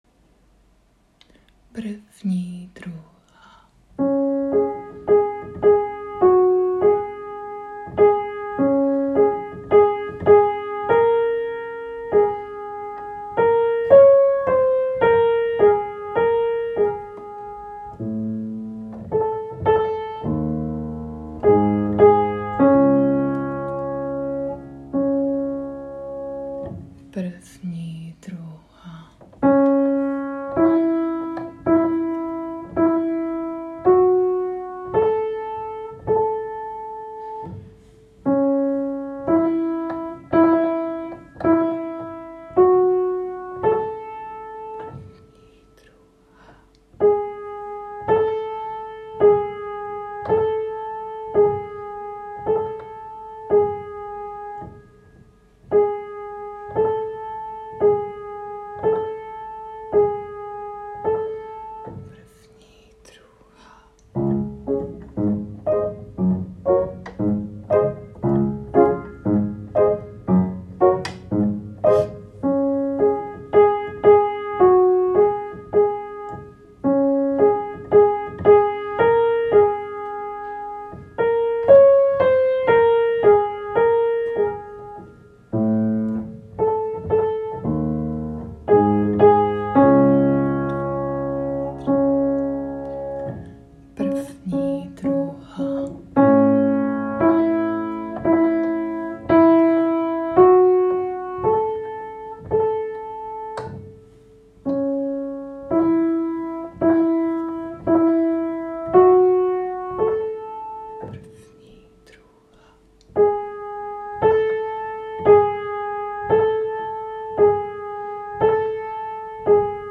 Představujeme si hlasy ostatních zpěváčků a zpíváme sami s melodií písničky, kterou nám hraje klavír:
Klikněte ZDE - PAMPELIŠKY /  MELODIE